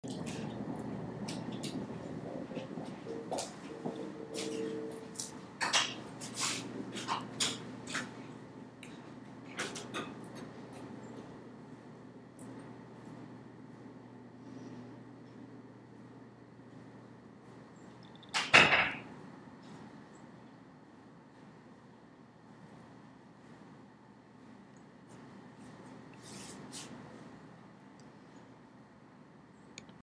Location: Outside of Calkins hall, 8:50 AM. You can hear the shuffling of various people on their morning commute, the dull sound of cars in the background, and the door to Calkins lab opening and closing. It is very still and crisp outside, and no one seems to want to break that silence.